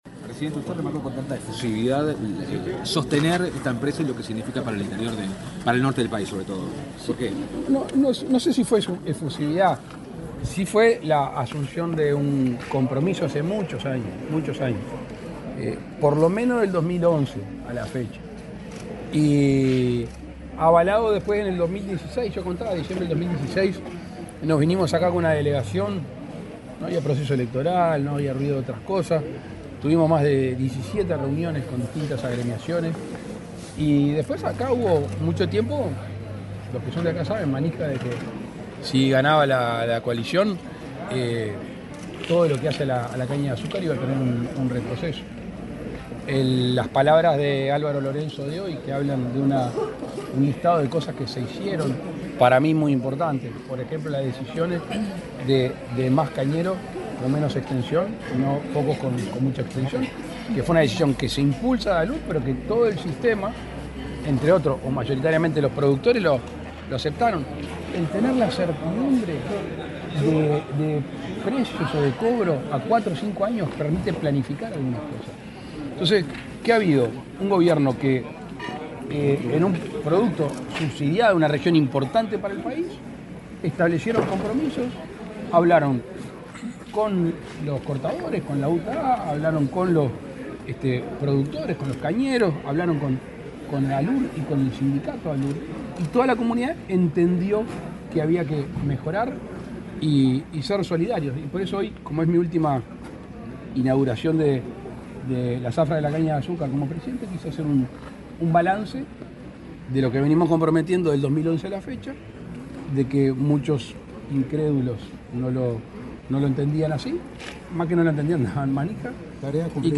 Declaraciones del presidente Lacalle Pou a la prensa
Declaraciones del presidente Lacalle Pou a la prensa 09/05/2024 Compartir Facebook X Copiar enlace WhatsApp LinkedIn El presidente Luis Lacalle Pou, dialogó con la prensa, luego de encabezar, este jueves 9 en la planta de Alur en Bella Unión, en Artigas, el acto de inicio de la Zafra 2024 de Caña de Azúcar.